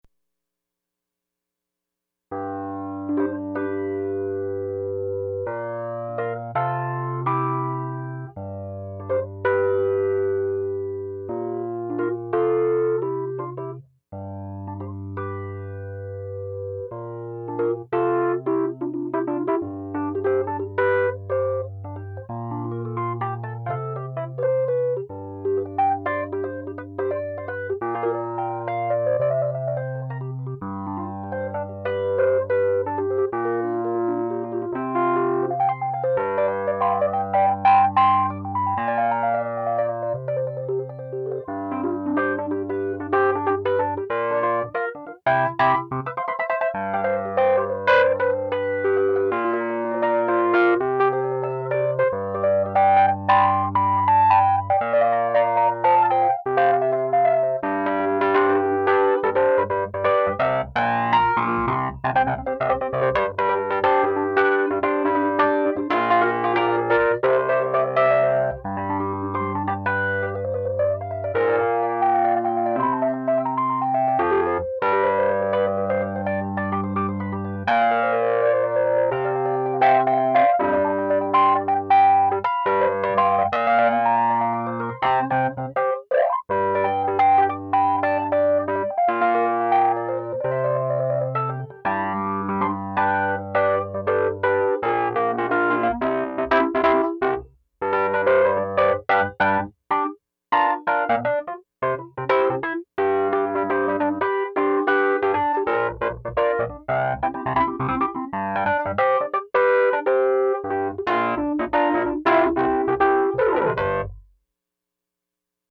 Nord Keyboard Demos
So I did, in the form of a Nord Stage 76 (rev. b) and a Nord G2X.
grindy-rhodes.mp3